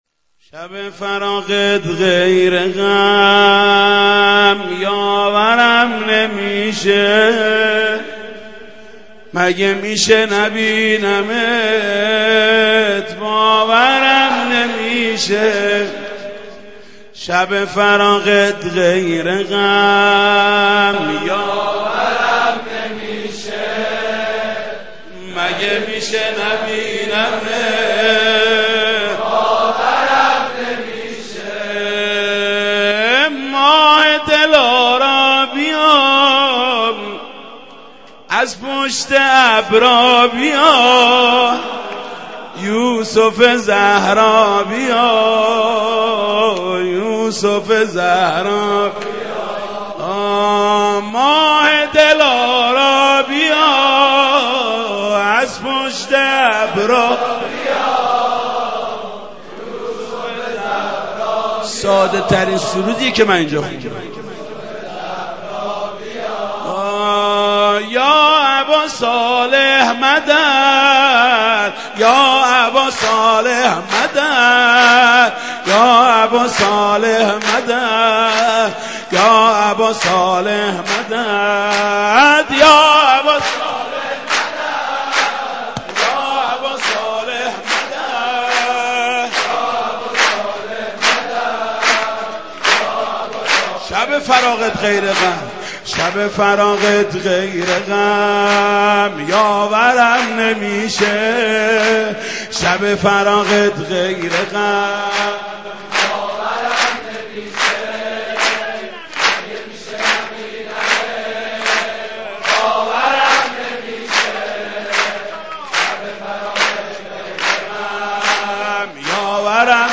مداحی ماندگار و خاطره انگیز